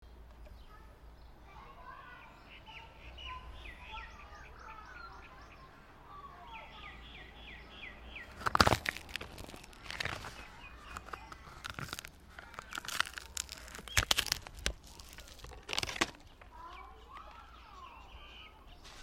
Tree Bark Crackle And Snap Gore Sound Effect Download: Instant Soundboard Button